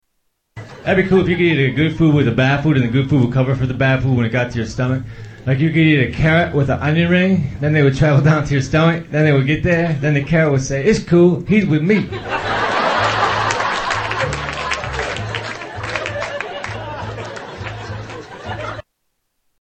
Tags: Comedians Mitch Hedberg Sounds Mitchell Lee Hedberg Mitch Hedberg Clips Stand-up Comedian